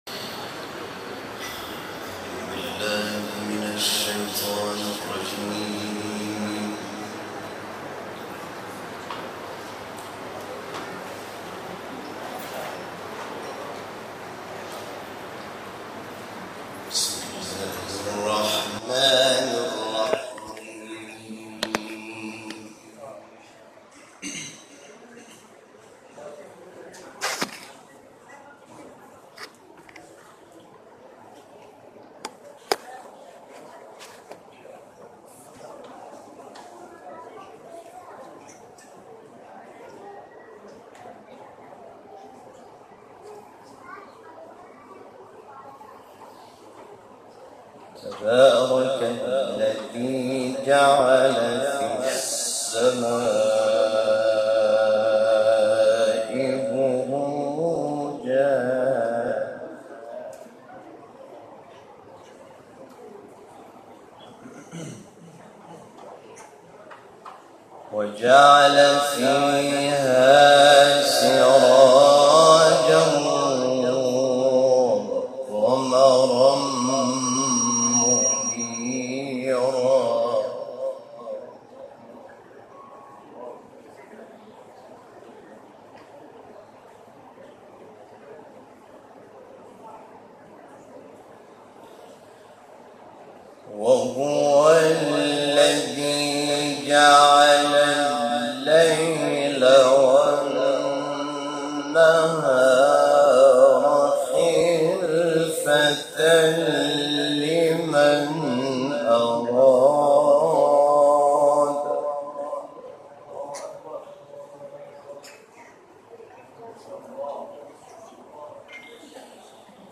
تلاوت تصویری «حامد شاکرنژاد» در گیلان
تلاوت حامد شاکرنژاد در خمام